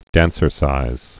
(dănsər-sīz)